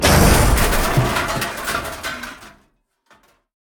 crash2.ogg